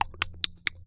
TONGUE TIMER.wav